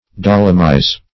dolomize \dol"o*mize\, v. t. To convert into dolomite.